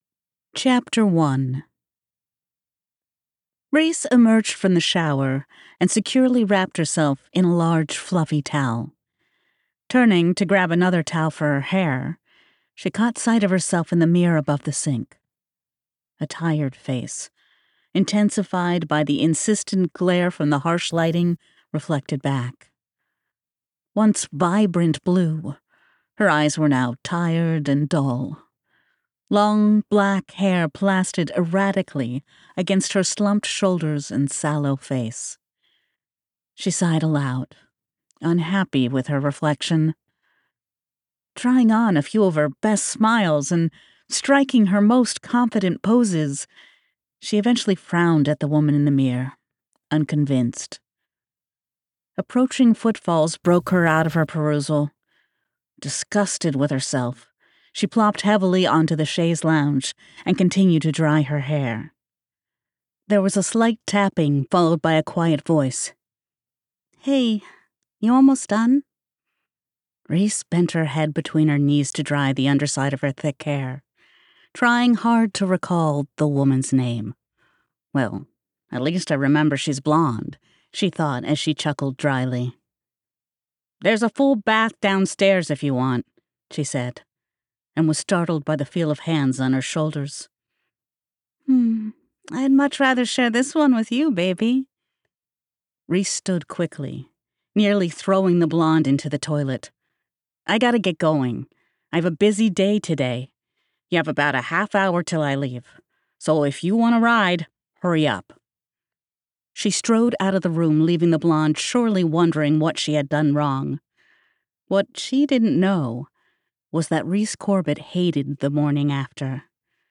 Private Dancer by TJ Vertigo [Audiobook]